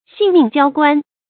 性命交关 xìng mìng jiāo guān
性命交关发音